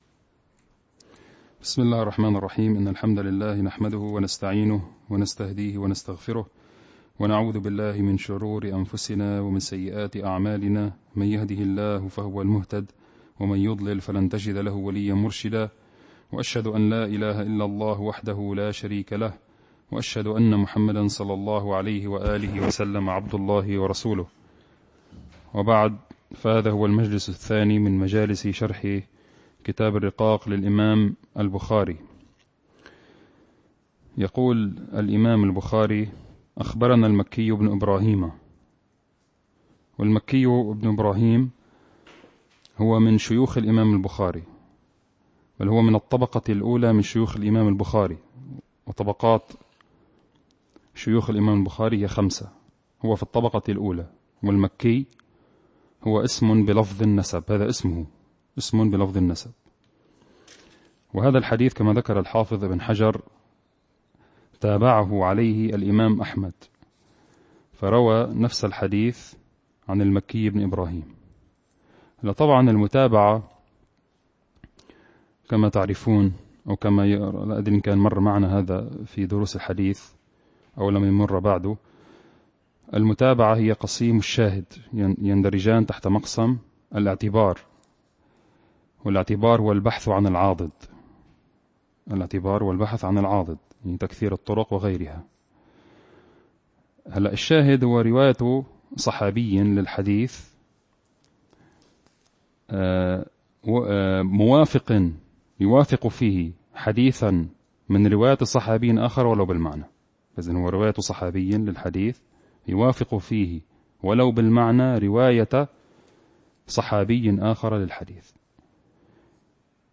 المكان : مركز جماعة عباد الرحمن